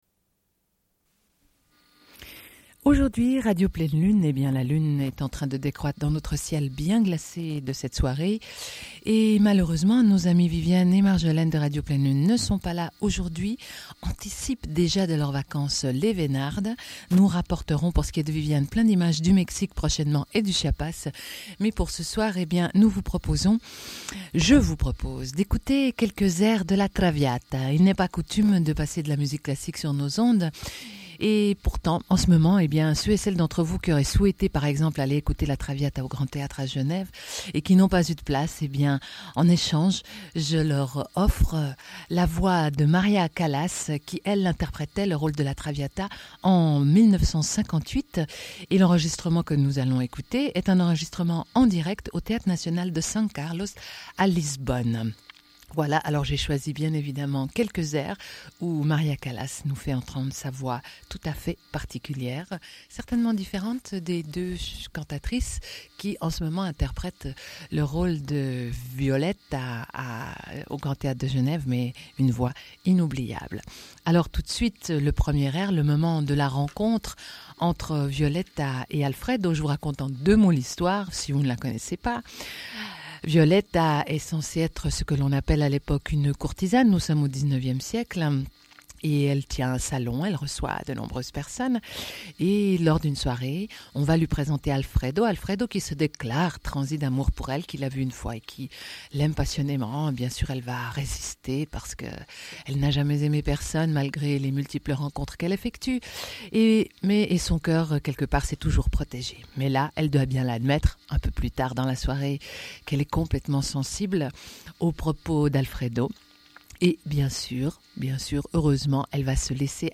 Airs
Radio Enregistrement sonore